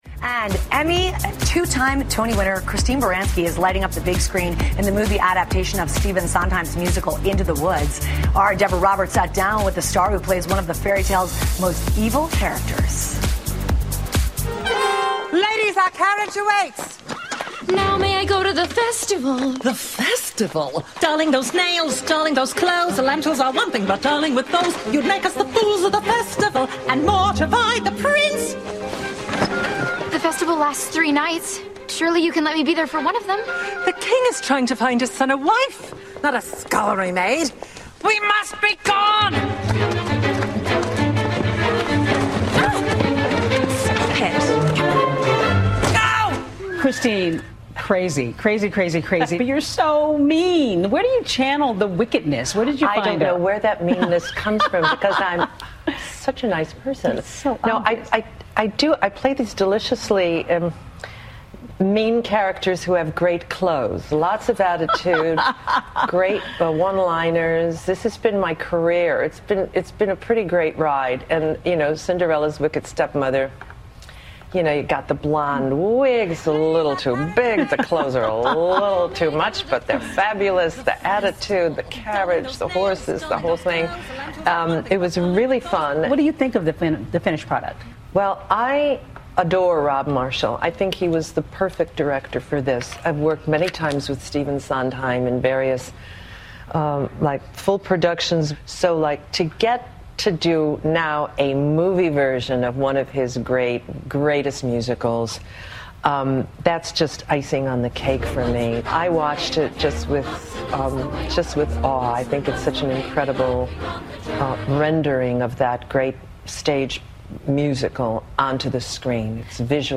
访谈录 灰姑娘继母克里斯汀·芭伦斯基谈《魔法黑森林》 听力文件下载—在线英语听力室